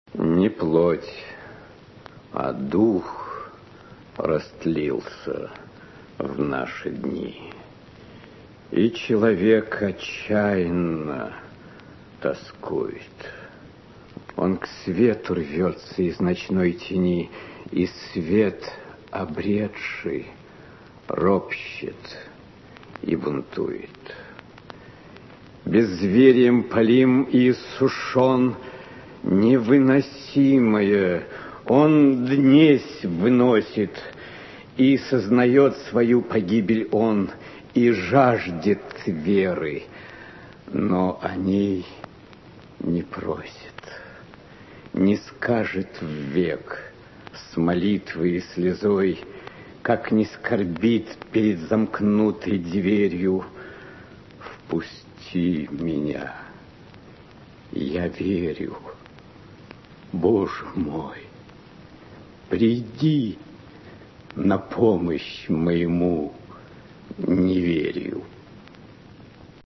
Стихотворение Тютчева «Наш век» читает Иннокентий Смоктуновский (скачать)